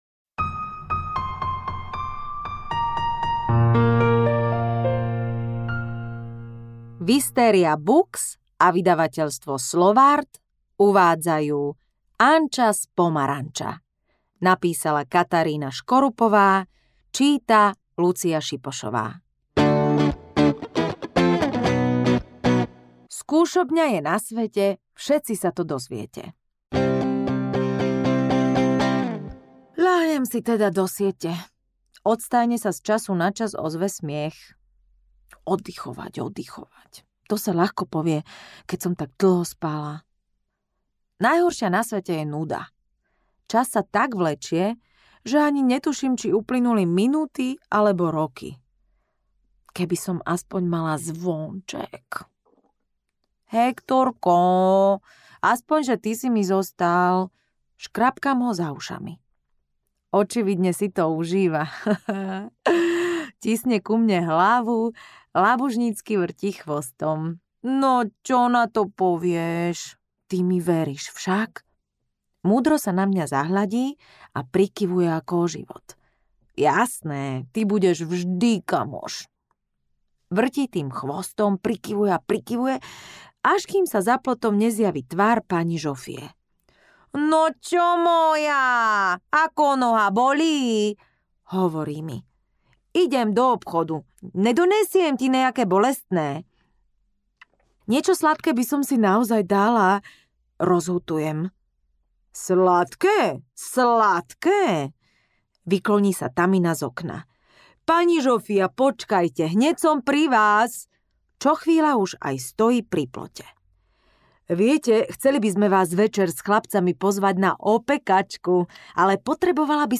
Anča z pomaranča audiokniha
Ukázka z knihy
• InterpretLucia Siposová